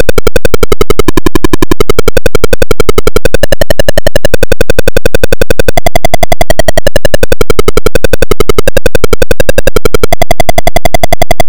In-game tune